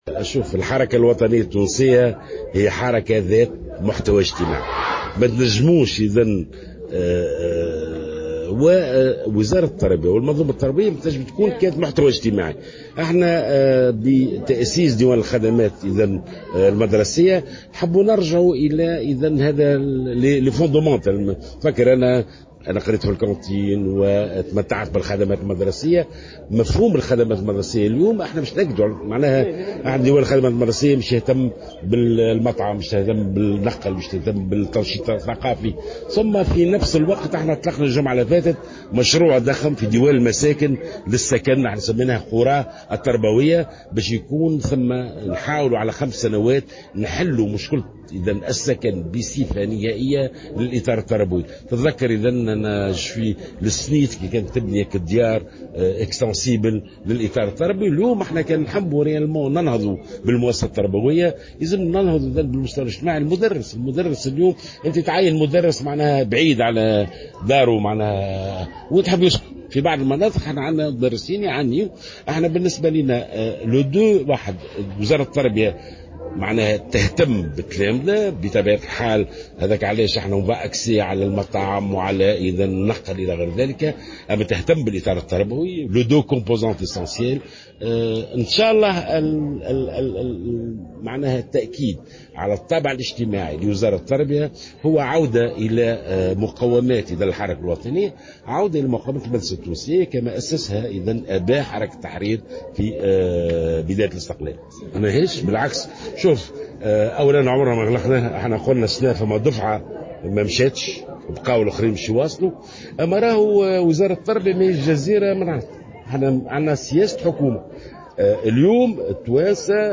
أشار وزير التربية ناجي جلول في تصريح للجوهرة أف أم، أمس الثلاثاء، إلى أن الوزارة على استعداد لإعادة تأهيل قطاع التكوين المهني، بعد أن تم نقل مؤسساته إلى وزارة التشغيل والتكوين المهني، في حال وافقت كل الأطراف المتدخلة في الشأن التربوي على ذلك.